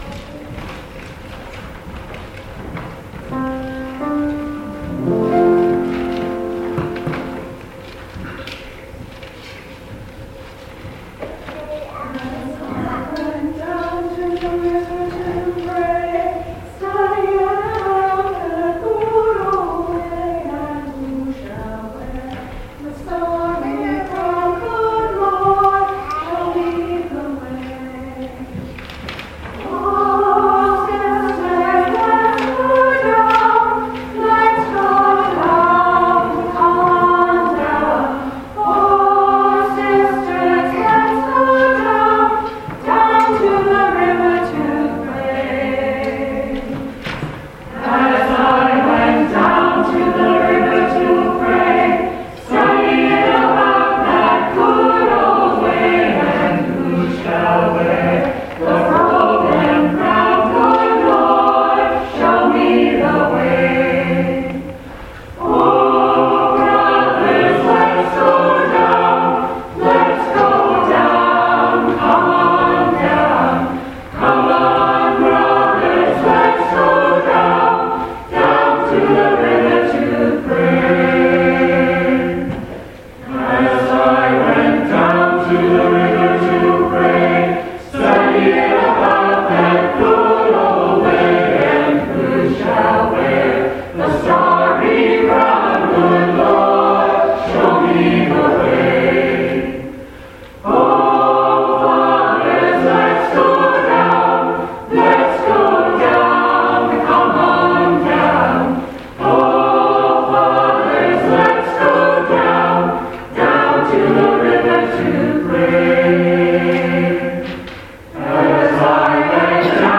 Down to the River MCC Senior Choir Offertory January 11, 2015 Download file Down to the River 2015